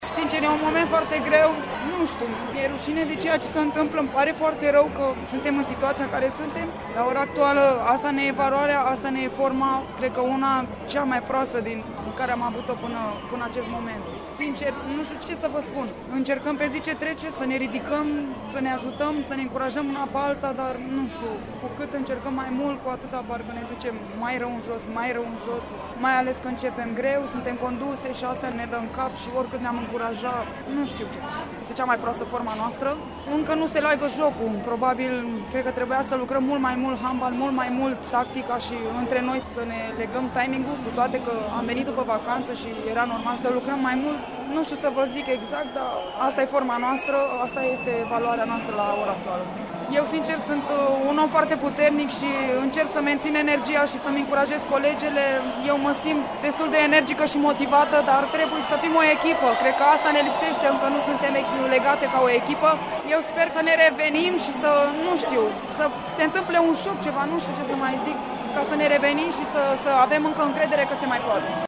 La final, jucătoarea României, Oana Manea, a declarat că nu își poate explica ce se întâmplă cu naționala feminină de handbal, aflată în cea mai slabă formă din ultimii ani buni.